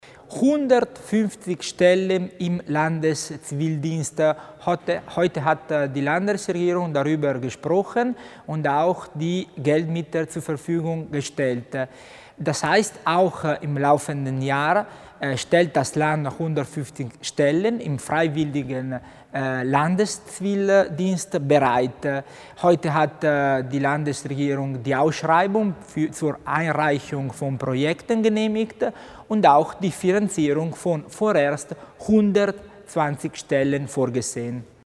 Landeshauptmannstellvertreter Tommasini über den freiwilligen Landeszivildienst